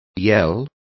Complete with pronunciation of the translation of yells.